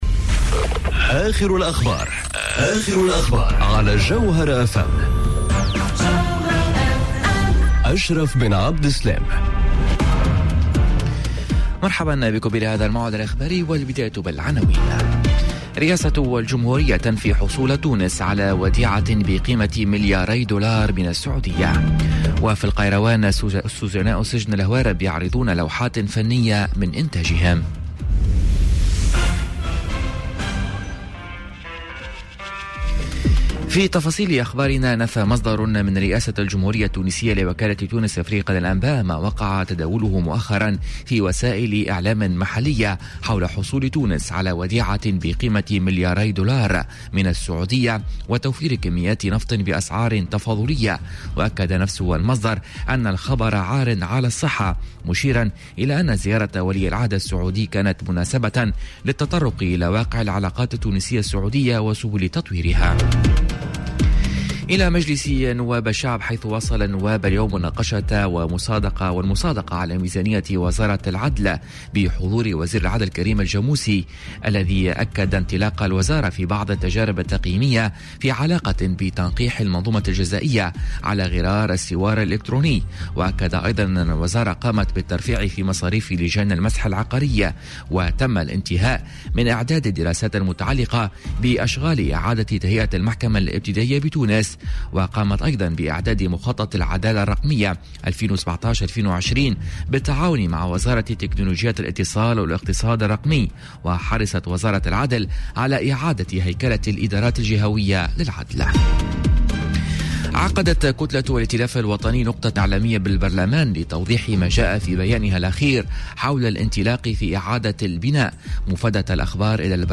Journal Info 12h00 du mercredi 28 novembre 2018